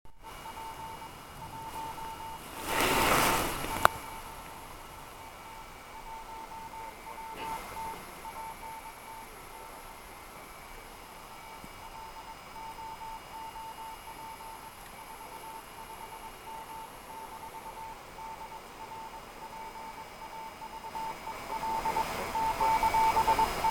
Прилагаю парочку коротких аудио- записей, как работает приёмник
прямого преобразования в реальном эфире, в лесах- полях.